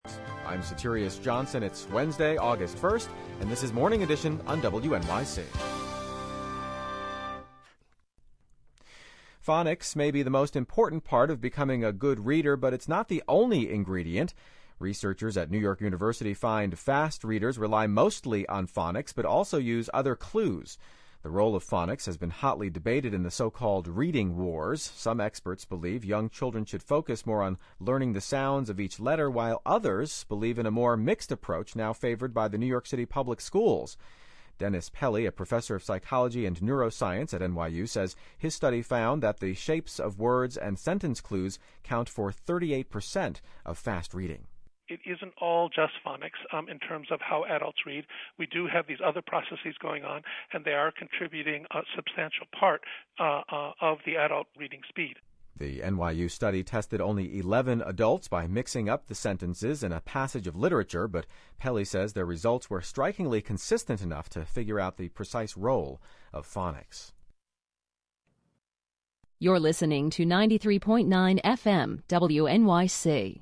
News reports: